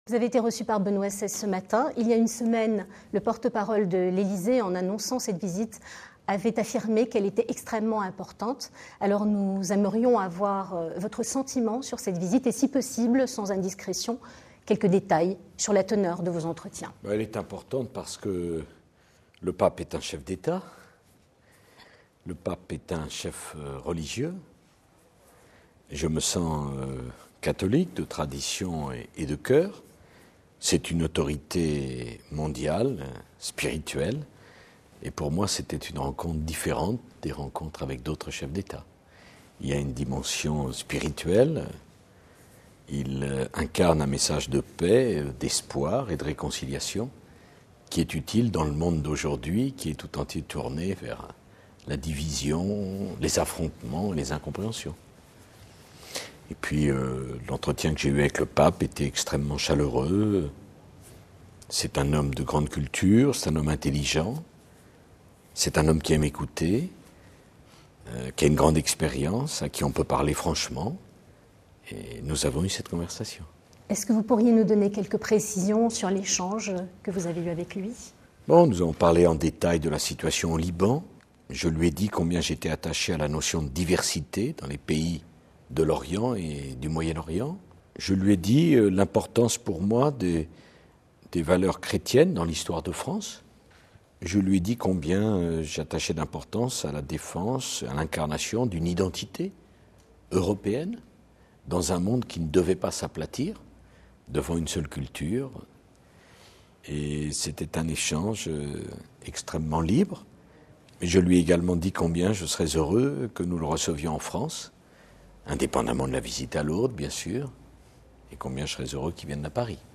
Ecoutez quelques morceaux choisis de l'interview que le président Sarkozy avait accordée aux médias du Saint-Siège (Radio Vatican, Osservatore Vaticano et CTV) en décembre 2007.
Quelques extraits choisis de cette interview donnée conjointement à Radio Vatican (RV), à l’Osservatore Romano (OR) et au CTV.